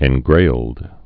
(ĕn-grāld)